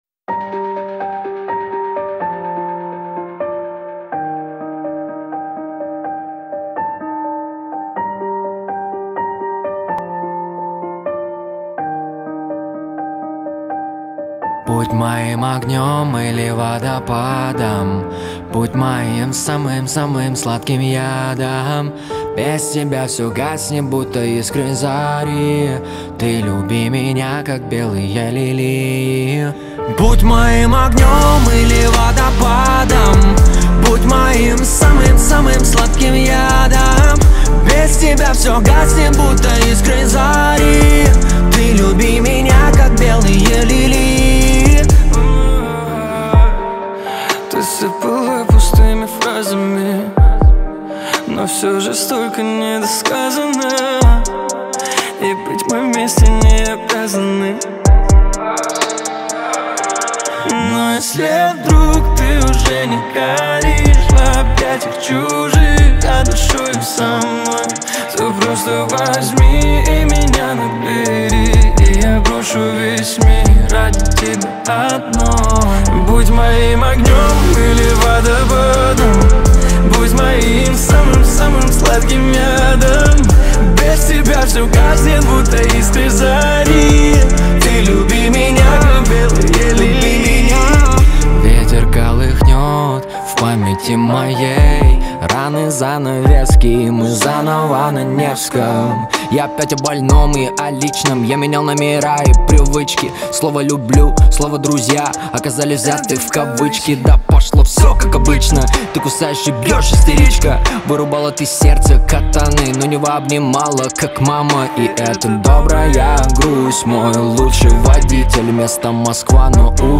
погружает слушателя в атмосферу глубокой эмоции и ностальгии